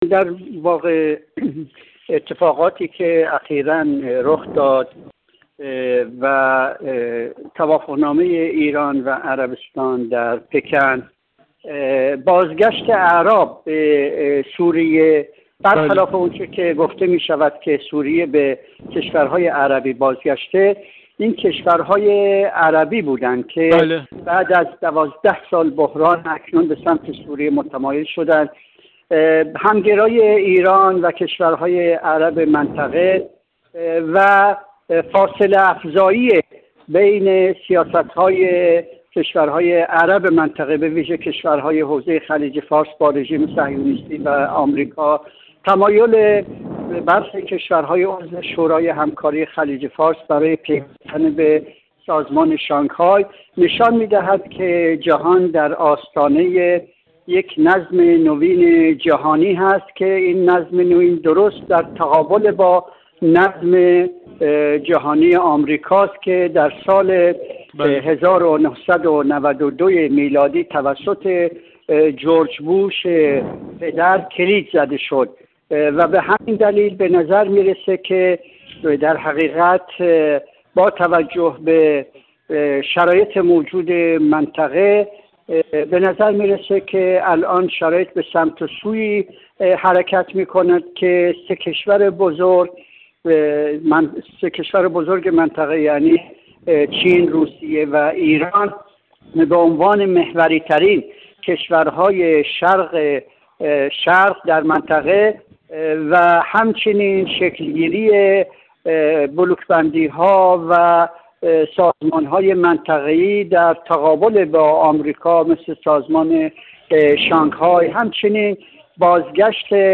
کارشناس مسائل منطقه